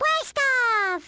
One of Baby Mario's voice clips from the Awards Ceremony in Mario Kart: Double Dash!!